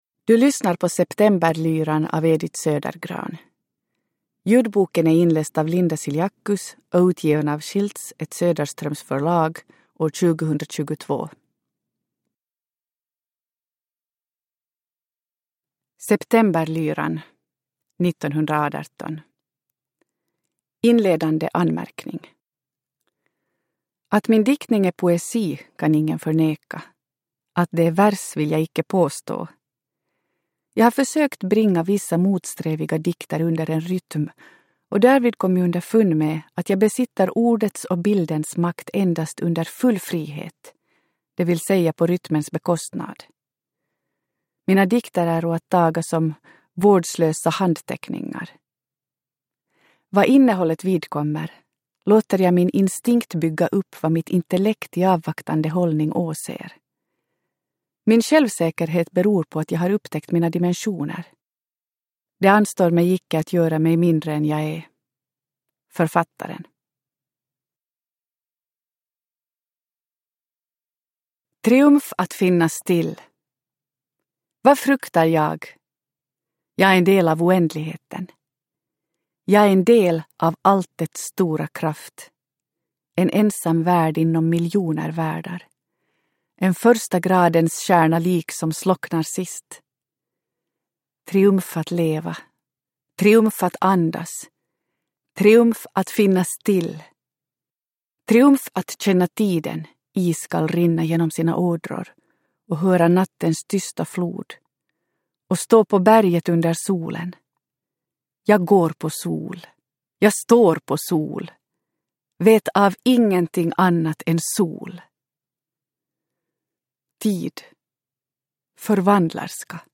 Septemberlyran – Ljudbok – Laddas ner